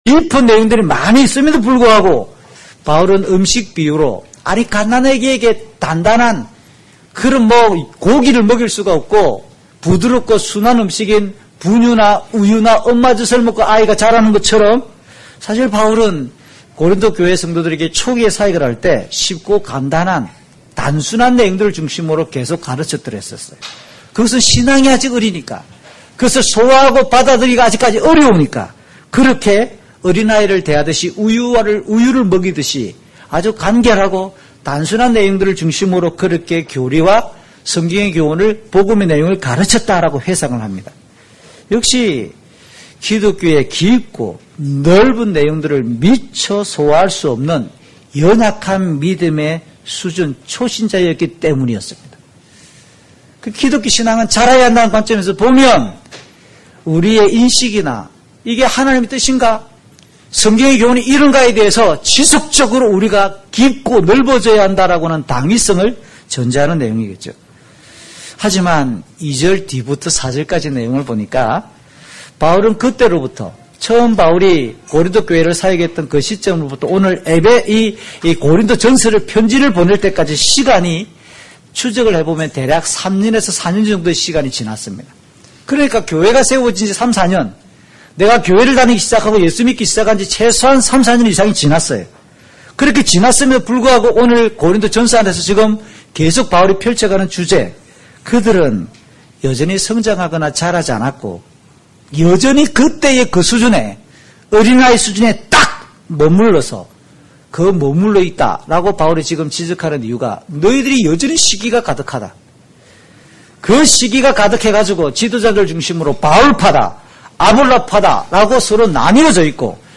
녹음문제로 앞부분 일부가 녹음되지 못했습니다